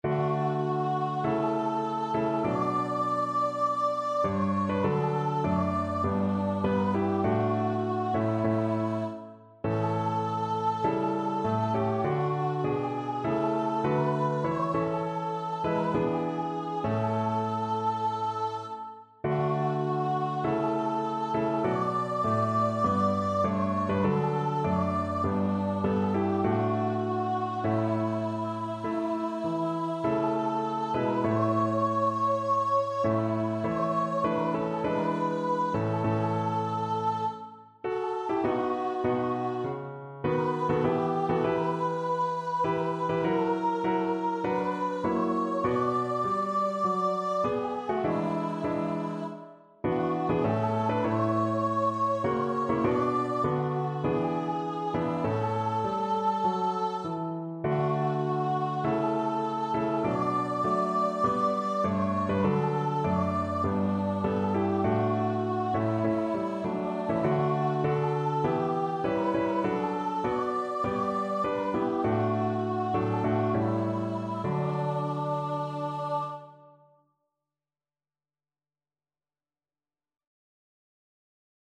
4/4 (View more 4/4 Music)
Classical (View more Classical Voice Music)
National Anthems